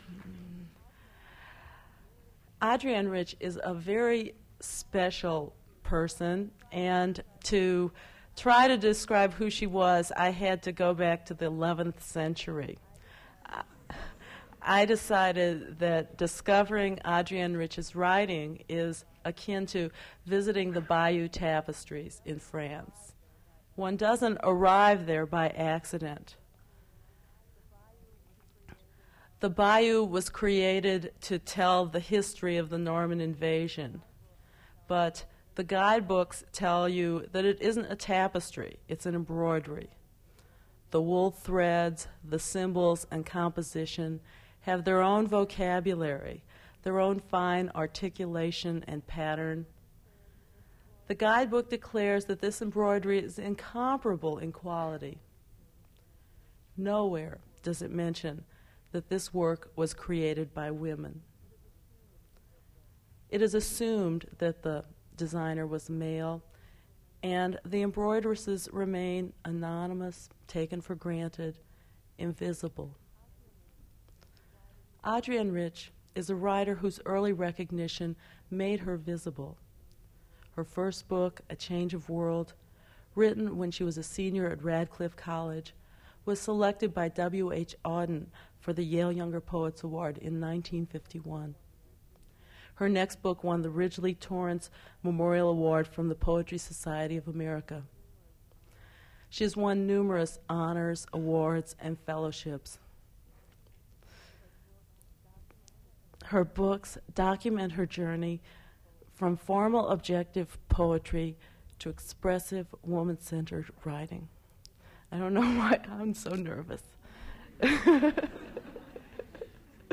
Attributes Attribute Name Values Description Adrienne Rich poetry reading at River Styx PM Series.
mp3 edited access file was created from unedited access file which was sourced from preservation WAV file that was generated from original audio cassette.